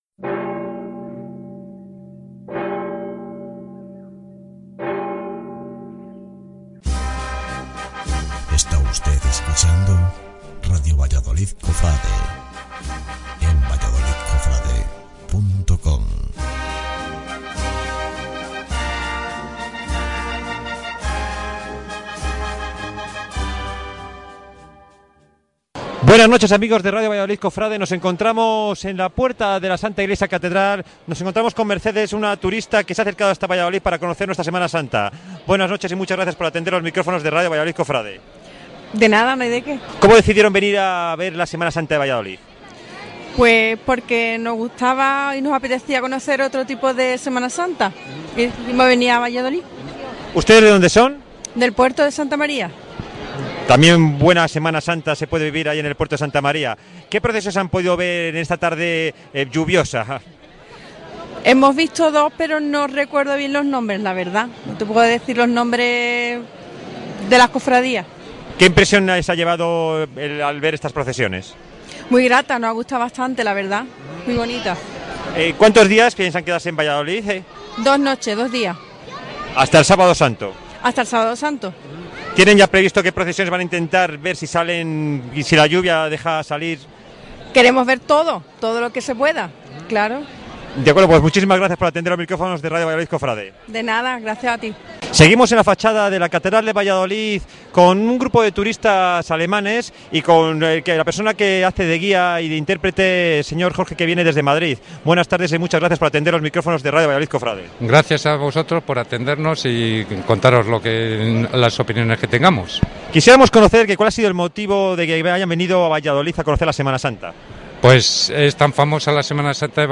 Entrevistas a turistas que acuden a Valladolid a conocer su Semana Santa